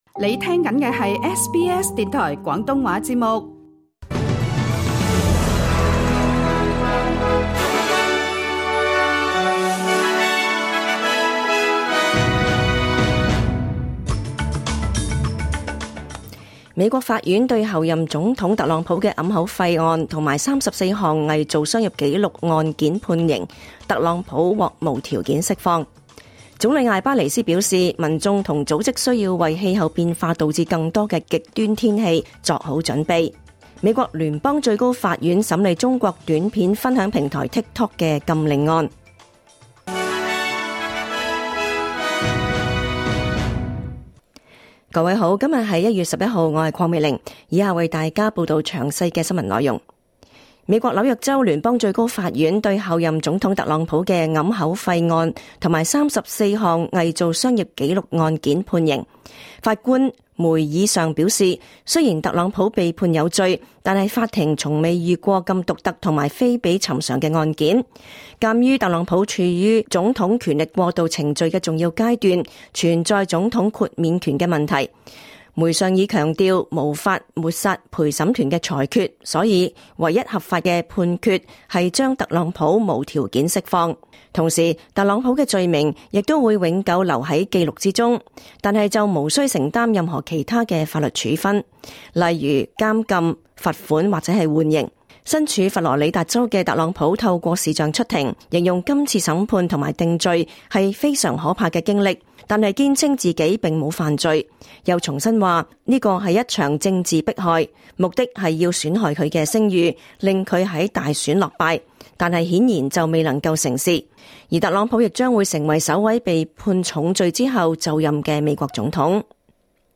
2025 年 1 月 11 日 SBS 廣東話節目詳盡早晨新聞報道。